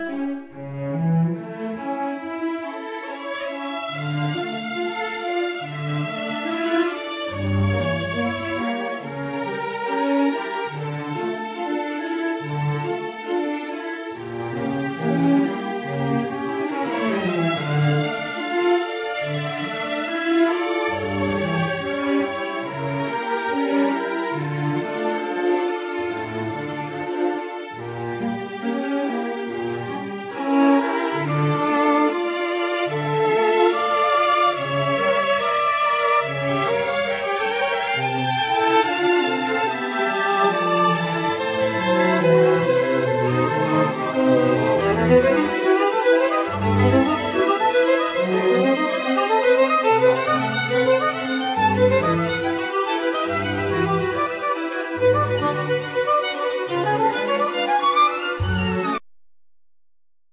Violin solo
Double bass
Accordeon
Guitar,Banjo,Melodica,Percussions